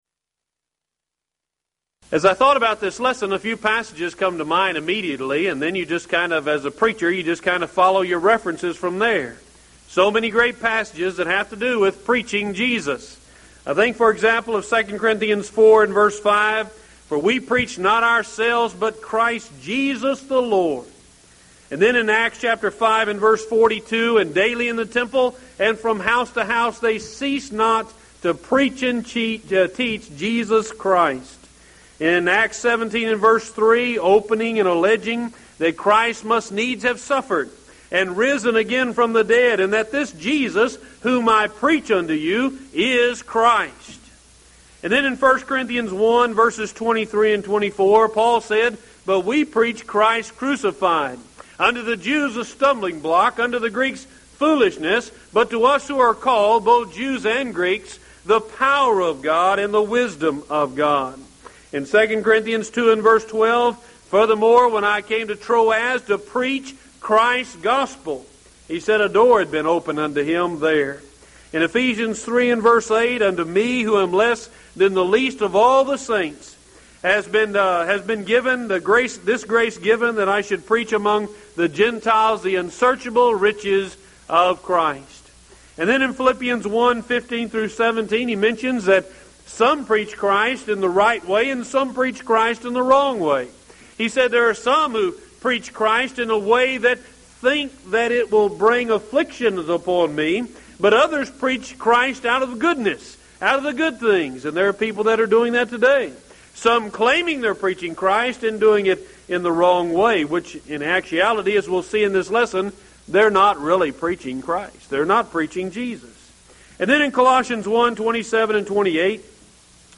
Event: 1998 Mid-West Lectures
lecture